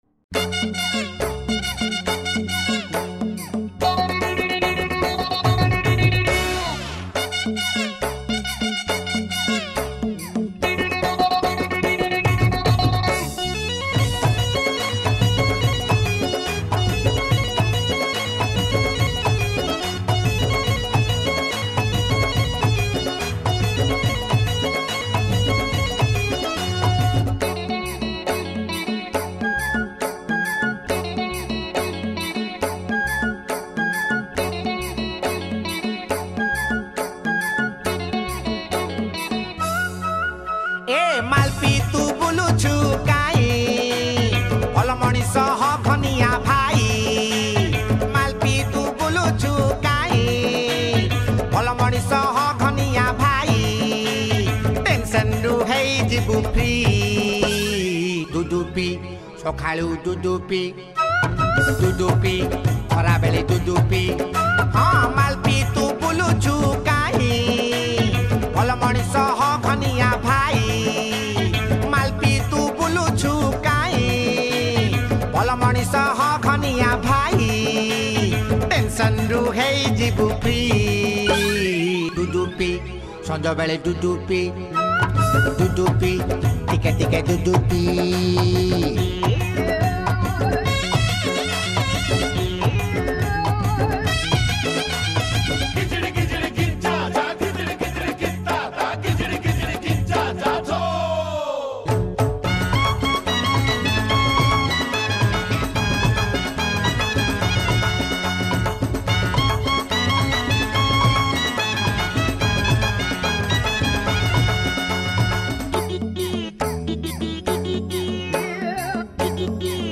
Odia Old Hits Mp3 Song